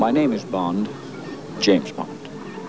Here are a few sound bites from the movie: